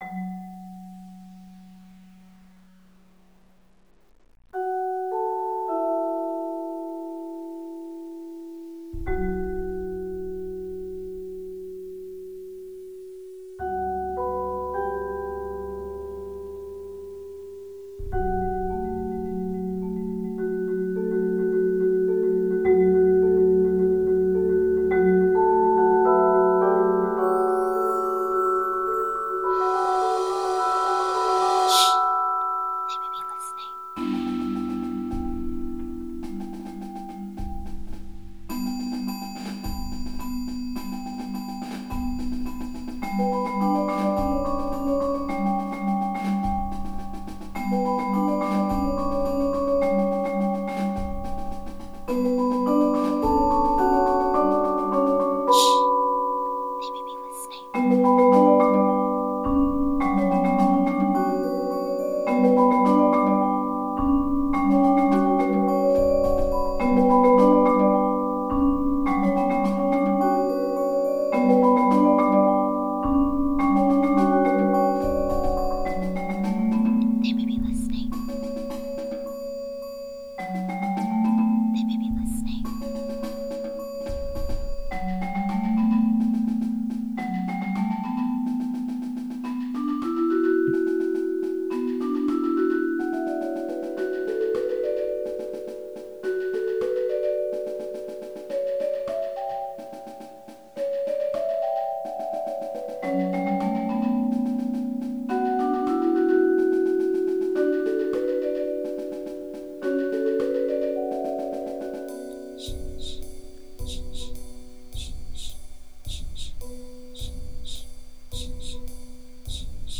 Genre: Percussion Ensemble
# of Players: 10
Vibraphone 1 (or optional 4-octave marimba)
Snare Drum 1
Percussion 1: Bass Drum, Wind Chimes